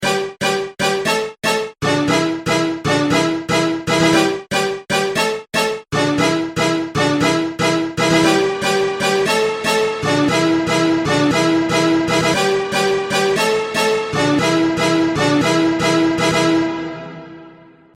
Orchestral Hit - Recreating the famous Fairlight Orch 2 Hit
Apparently it's as simple as sampling Stravinsky's Firebird. According to Peter Vogel, creator of the legendary 80s Fairlight Sampler/Synthesizer, the famous Orchestral Hit used in countless 80s pop classics, is a short excerpt from this classical masterpiece.